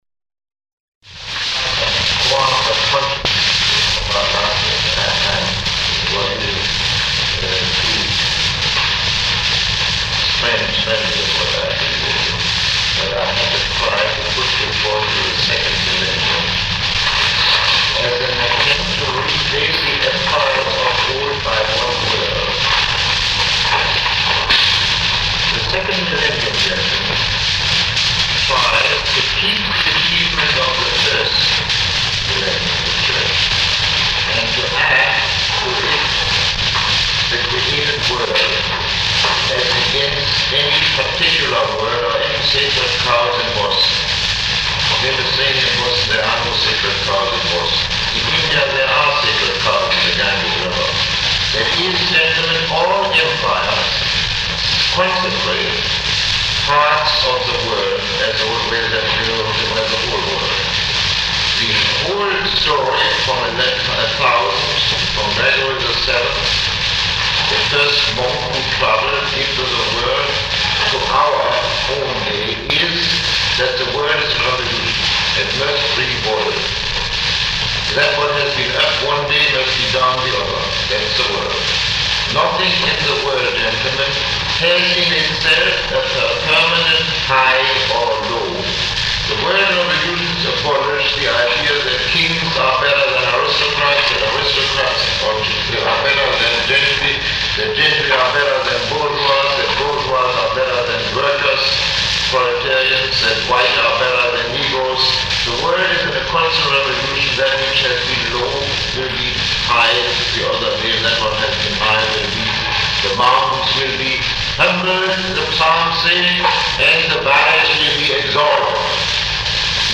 Lecture 4